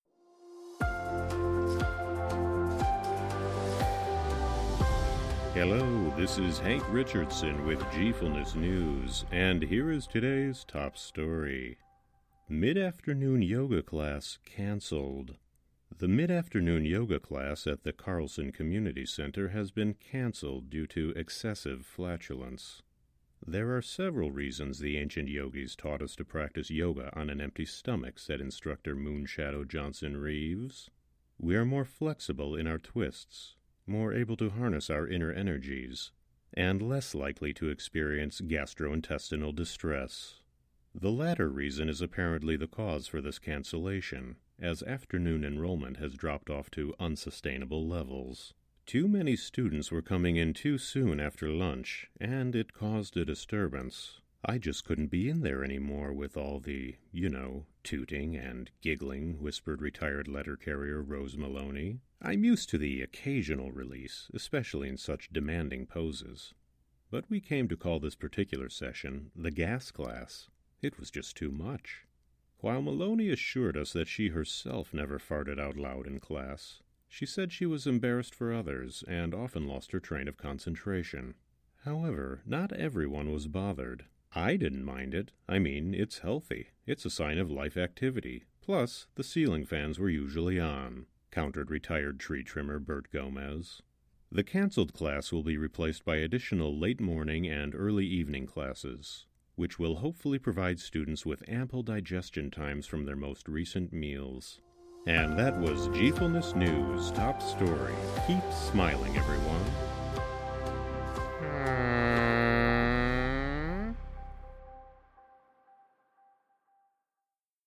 Genre:         NEWS